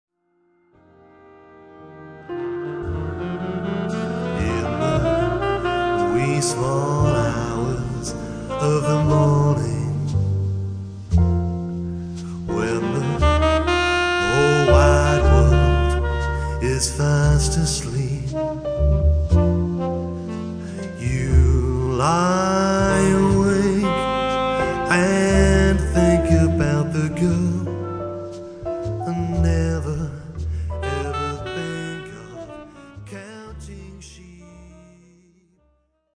with vocals
Cool and classy lounge sounds